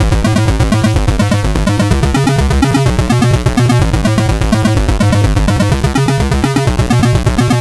Squelchy Sixteens F 126.wav